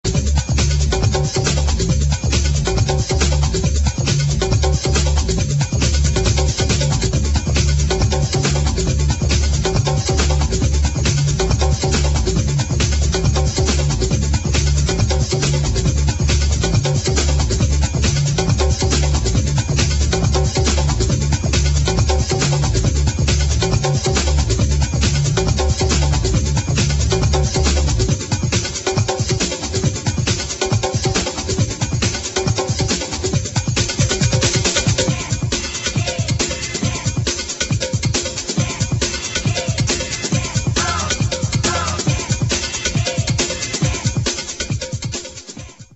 [ BREAKBEAT | BIG BEAT ]
ストレートなブレイクビーツ〜ビッグビート・サウンド！